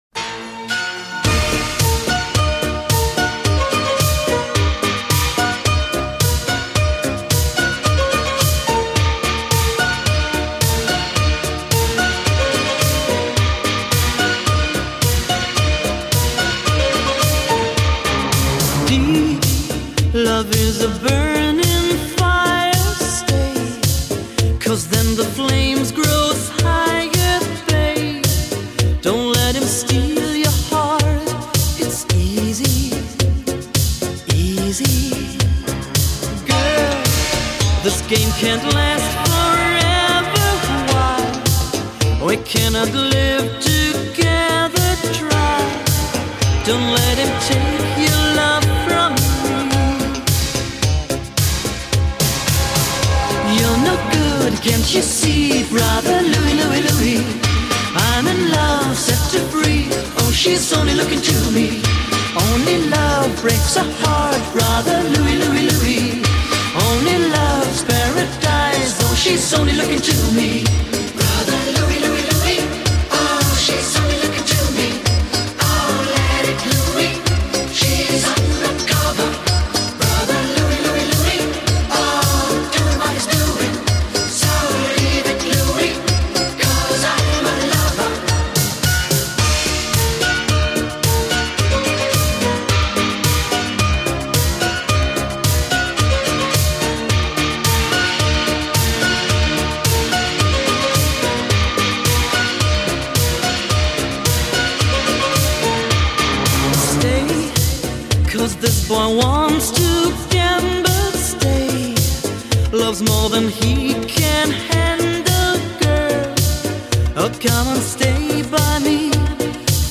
The greatest 80s music